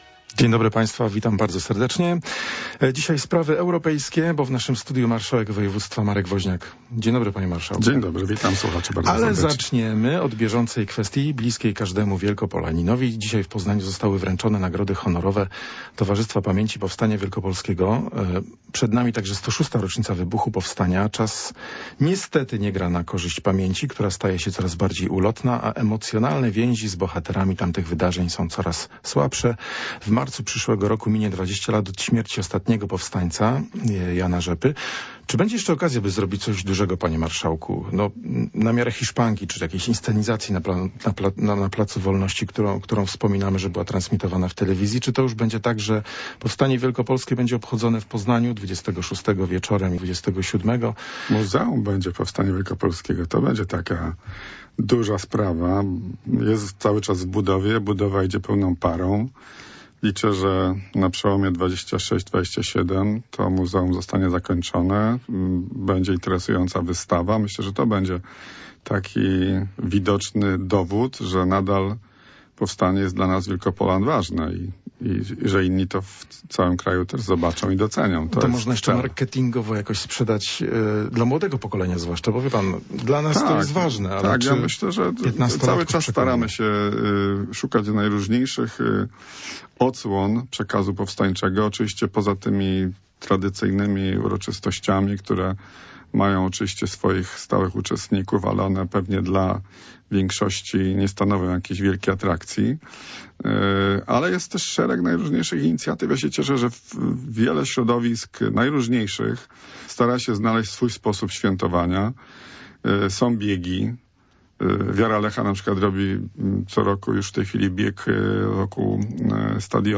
Popołudniowa rozmowa - Marek Woźniak